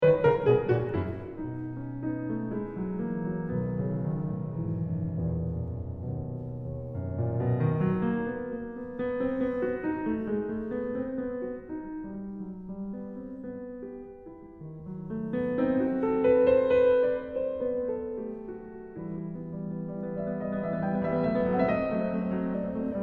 I have to apologize for the recording here, I haven’t gotten to the studio yet. So this is actually recorded at home, in my practicing studio. And as you can hear, I have practiced some, the piano is very out of tune.
A little sneaky, again…